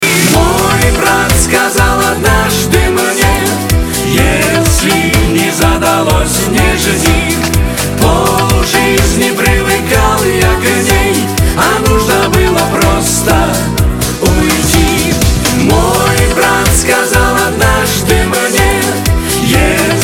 • Качество: 256, Stereo
мужской вокал